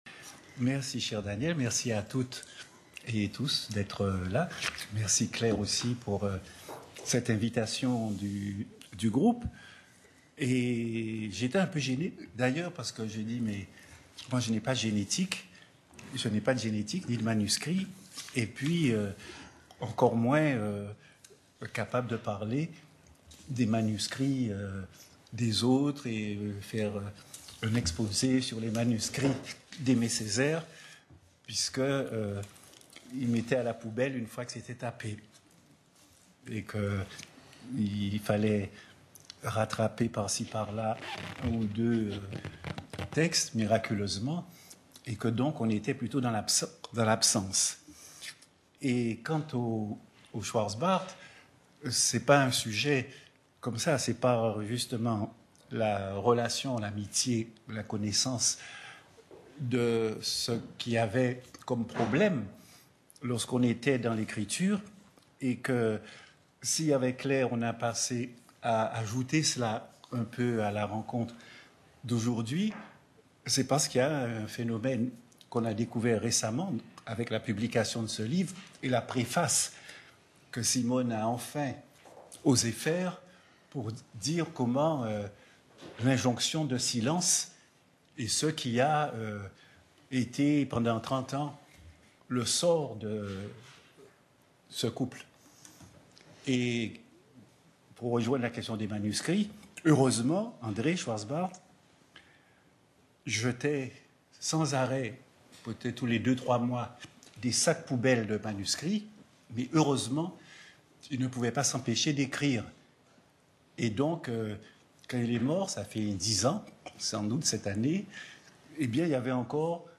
Introduction à sa conférence par Daniel Maximin. Où il est question notamment du rapport de Césaire à ses manuscrits…